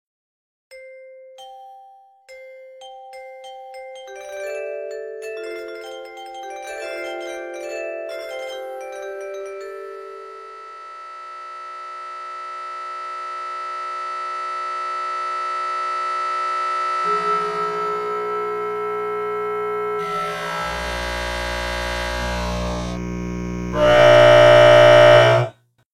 can the bouncing ball escape sound effects free download